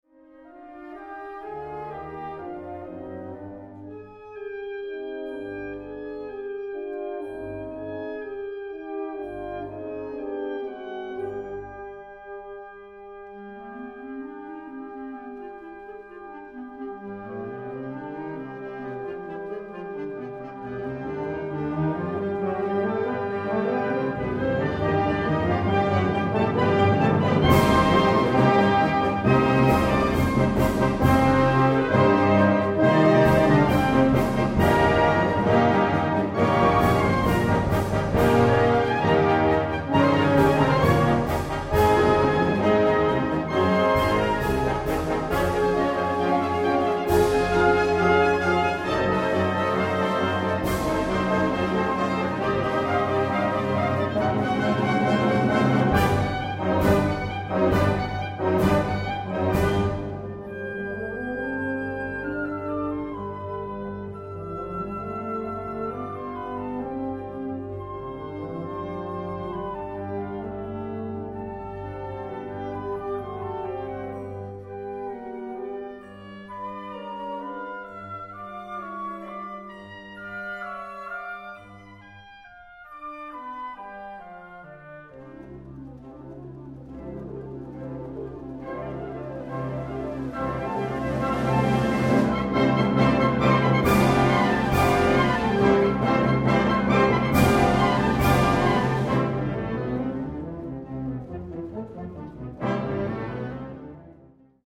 Key: C Minor (Original key)